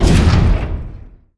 mega_bouncehard1.wav